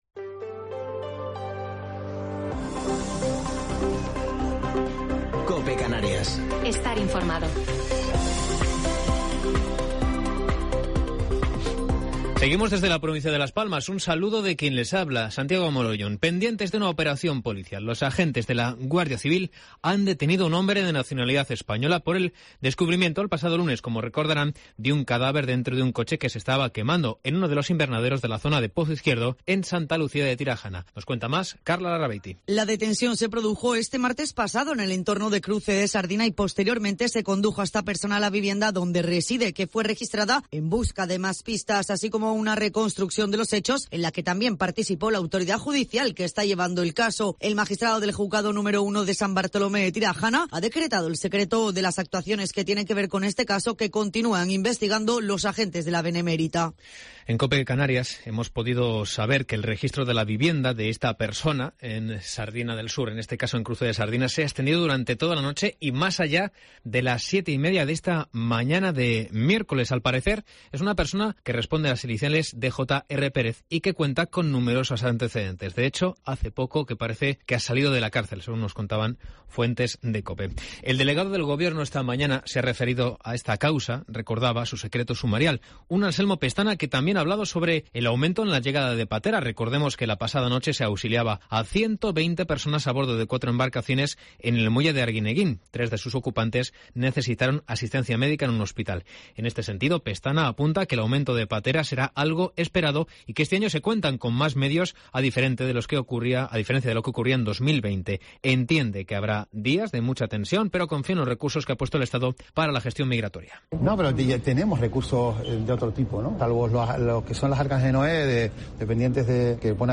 Informativo local 15 de septiembre de 2021